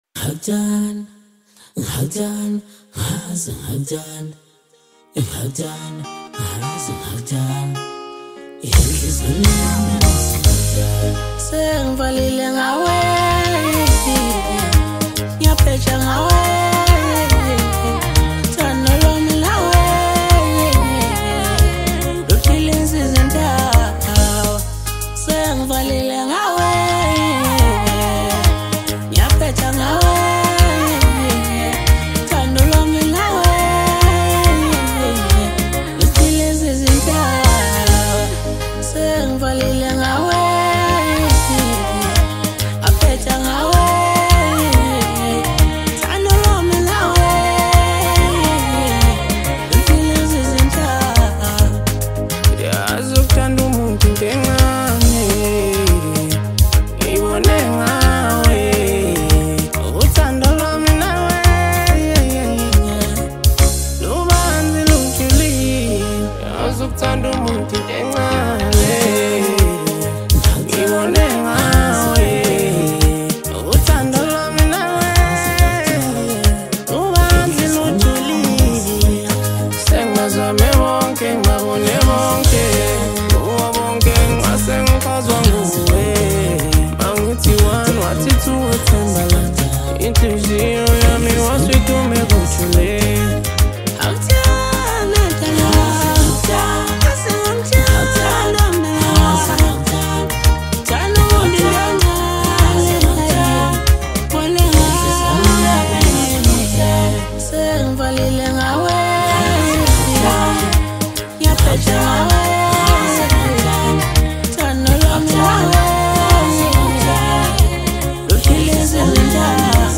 Maskandi, Amapiano, Hip Hop